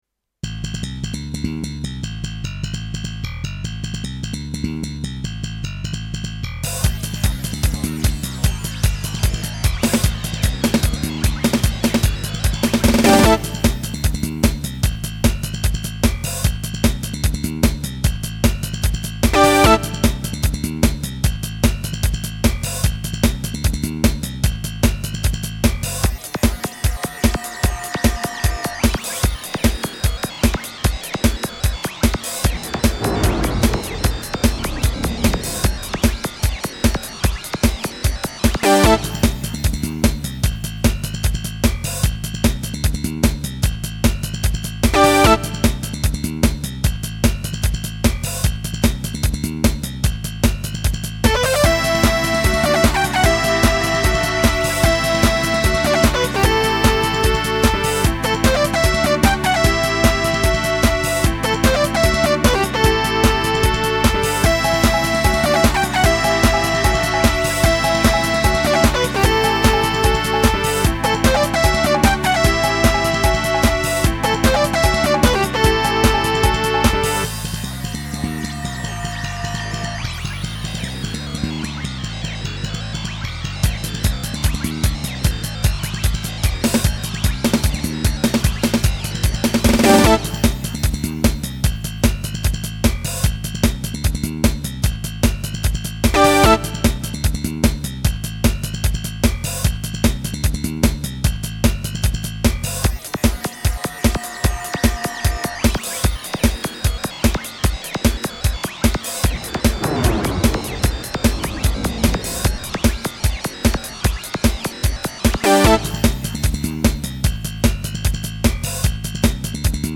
DTM
戦い